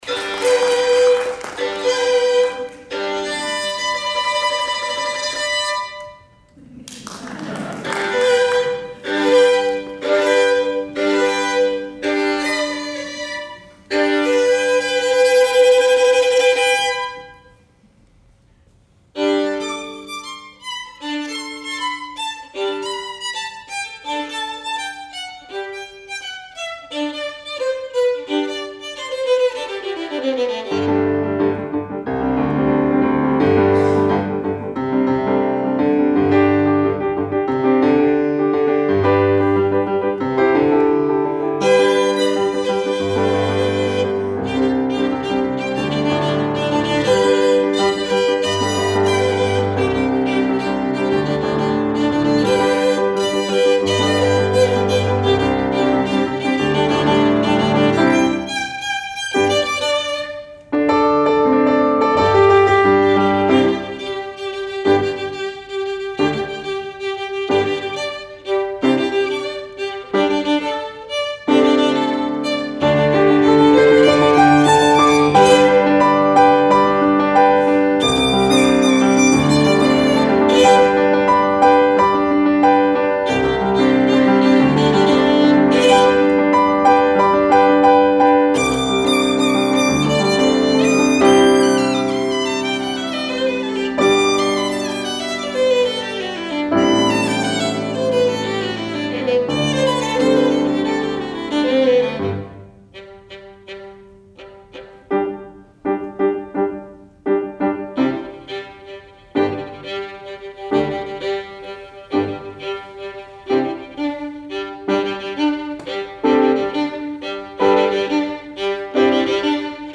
for Violin and Piano (2016)
piano.